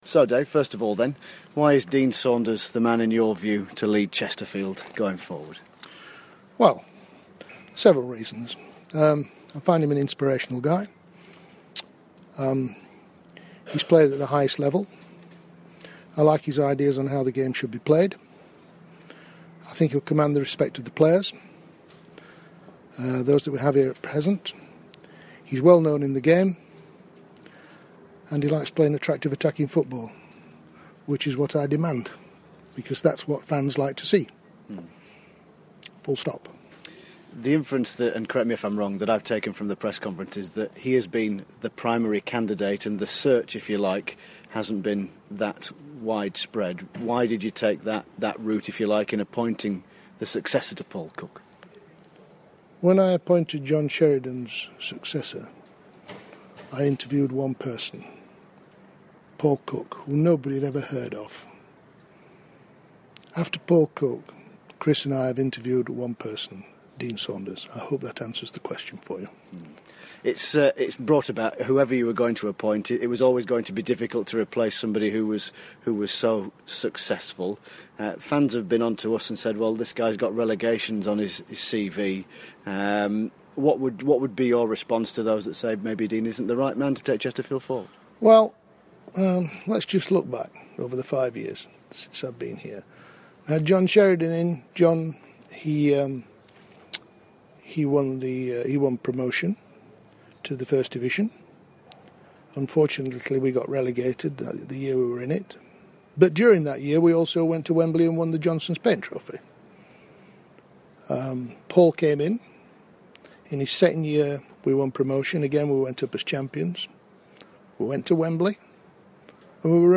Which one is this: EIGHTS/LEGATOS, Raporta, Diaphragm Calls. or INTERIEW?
INTERIEW